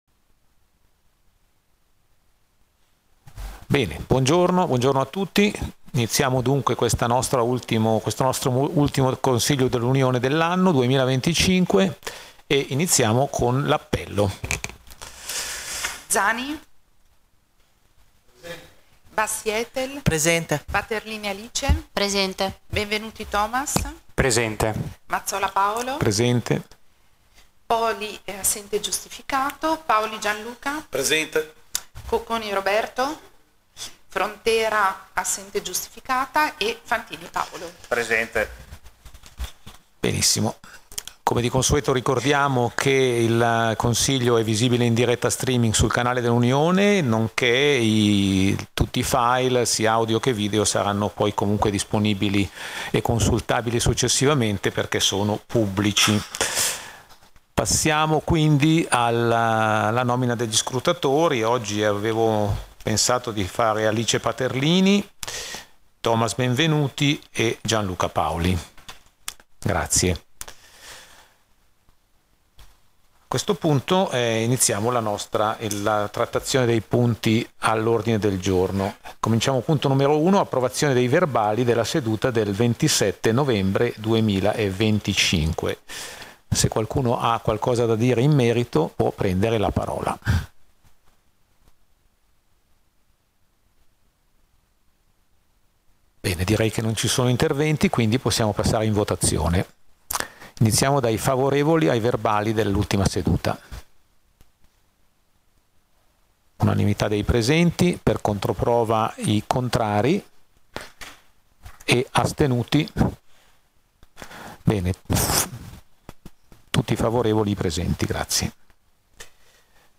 Seduta del Consiglio Unione del 29/12/2025